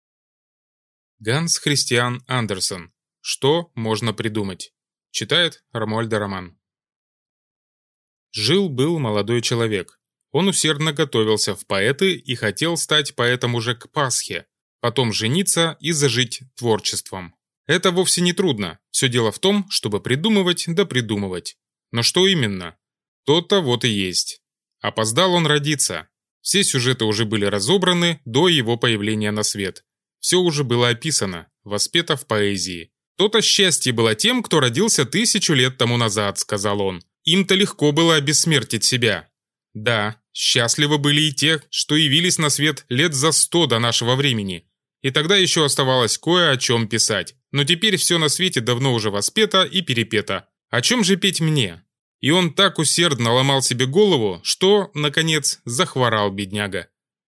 Аудиокнига Что можно придумать | Библиотека аудиокниг